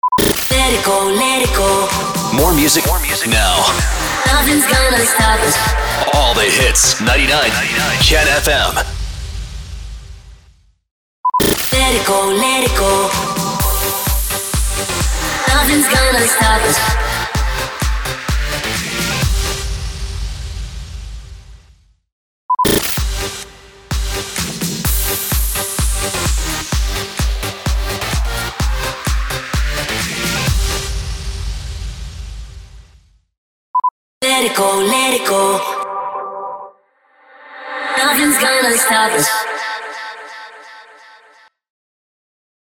421 – SWEEPER – MUSIC REJOIN
421-SWEEPER-MUSIC-REJOIN.mp3